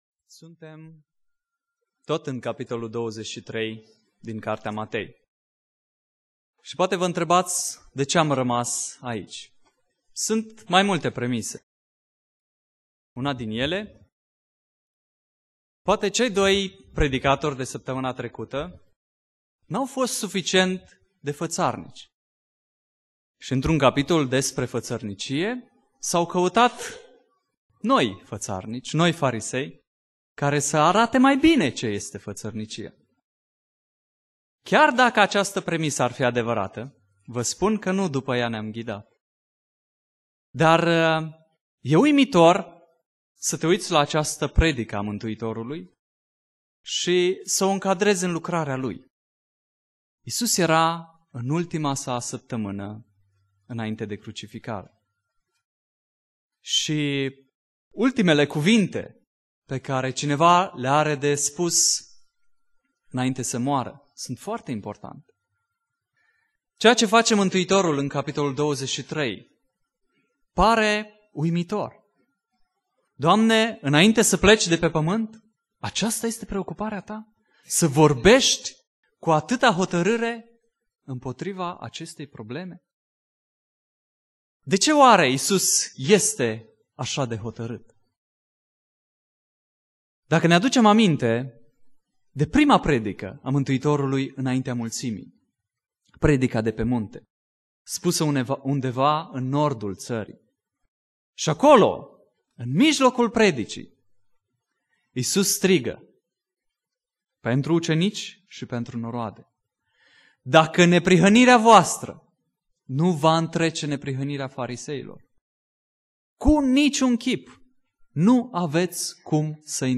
Predica Exegeza - Matei 23b